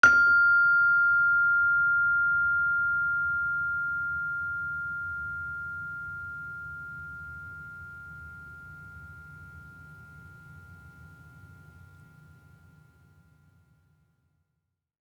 Gender-4-F5-f.wav